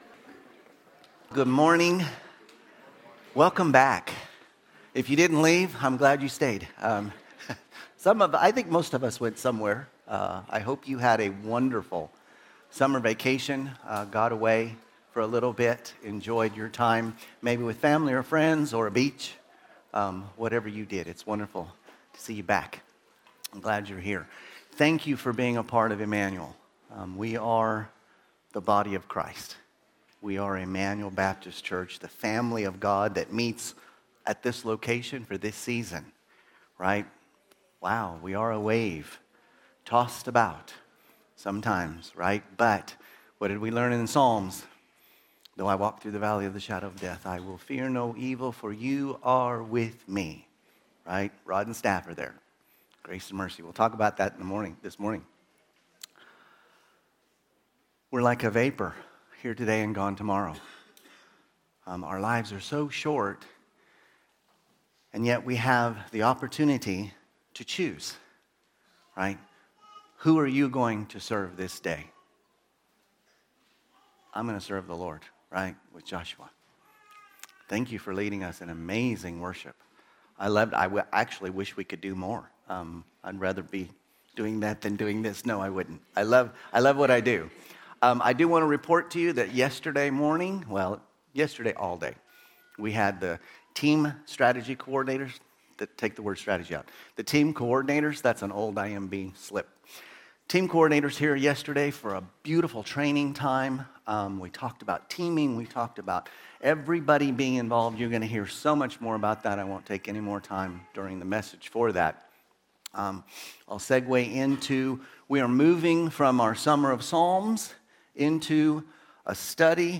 Sermons – Immanuel Baptist Church | Madrid